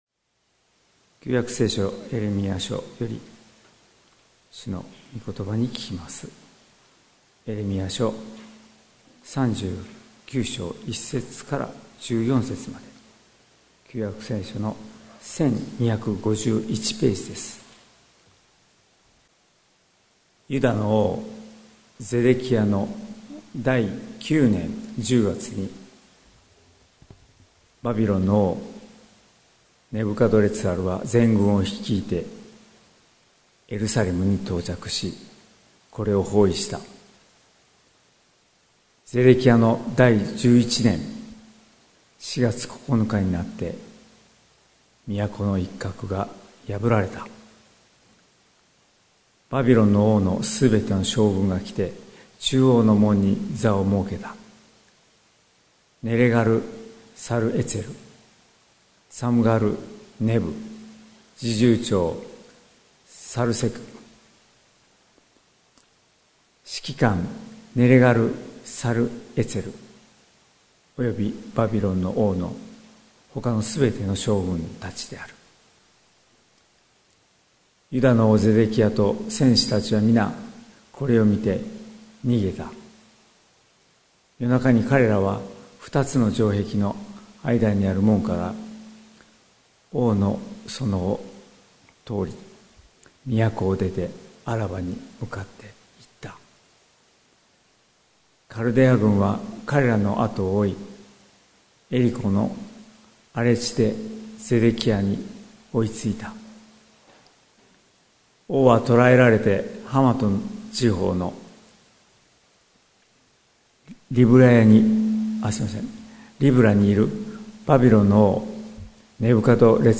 2024年05月13日朝の礼拝「あなたを必ず救い出す」関キリスト教会
説教アーカイブ。